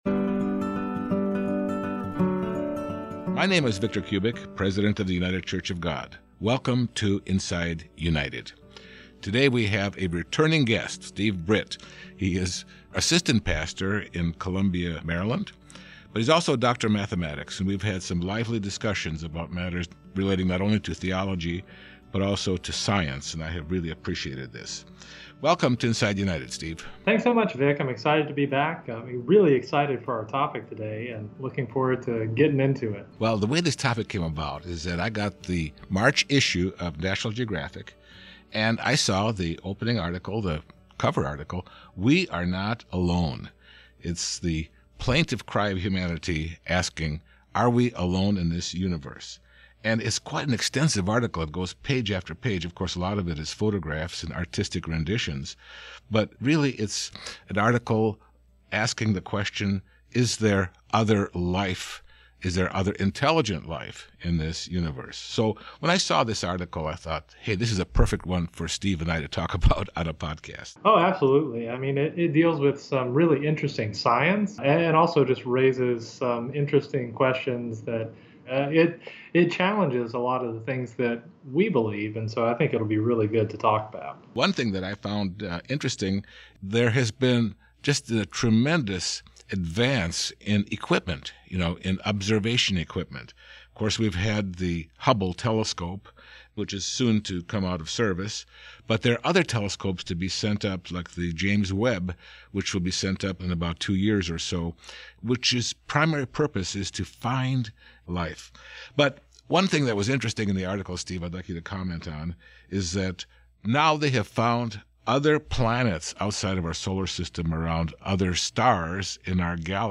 We revisit this interview